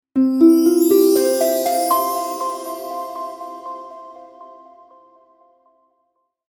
Elegant-harp-and-chimes-transition-sound-effect.mp3